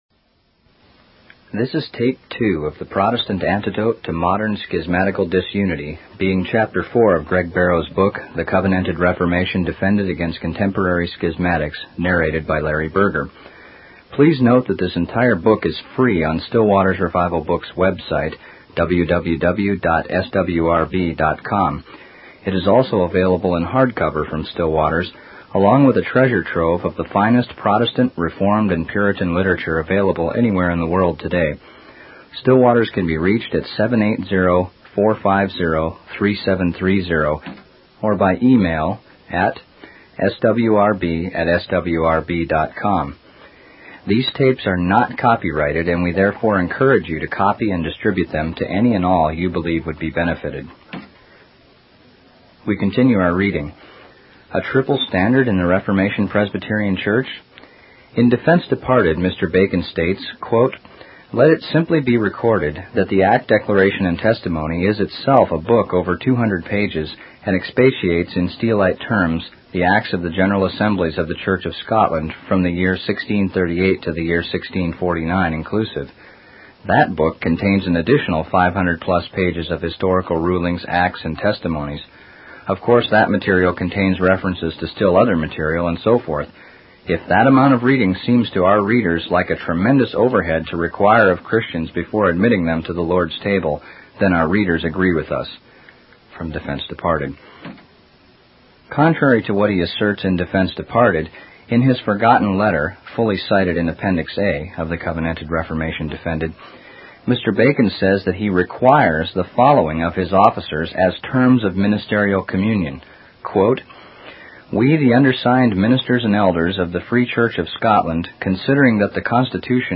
In this sermon transcript, the speaker addresses the issue of requiring historical testimony as a term of communion for ministers, elders, deacons, and probationers in the Reformation Presbyterian Church.